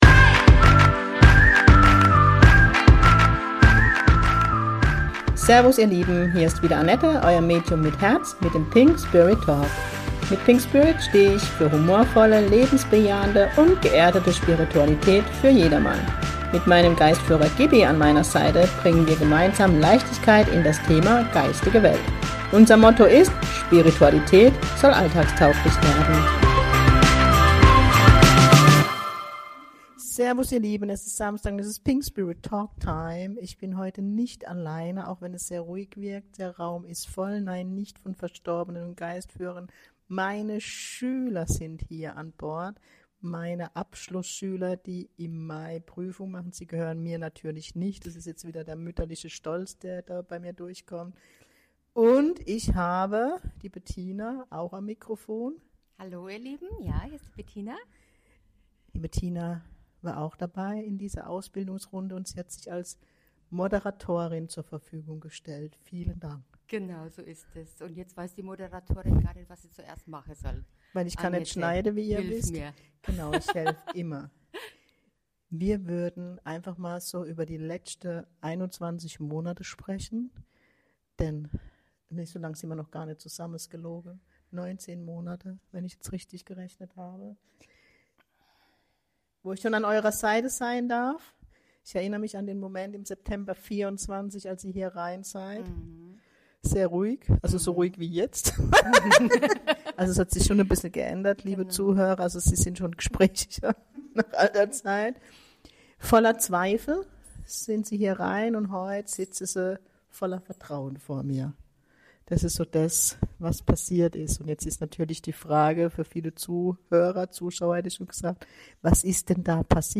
Es wird sehr emotional und deep. Da bleibt kein Auge trocken....